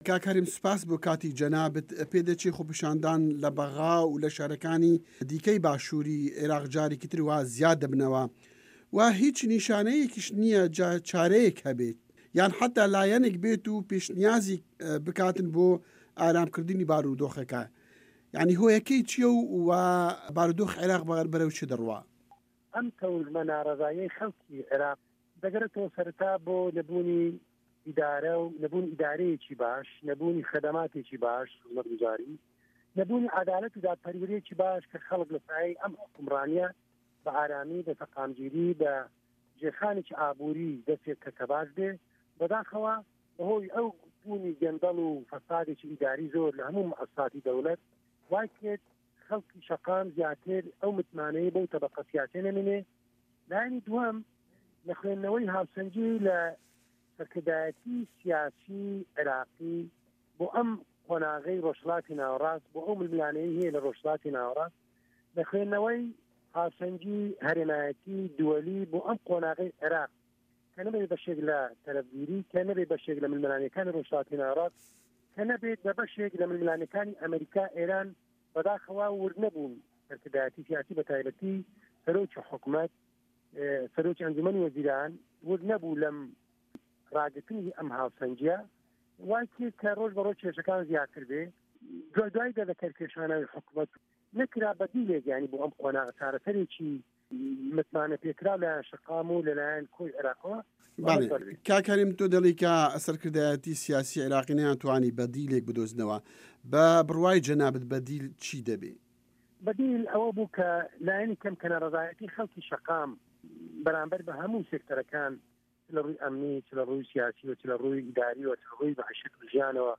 وتووێژ لەگەڵ هەرێم کەمال ئەغا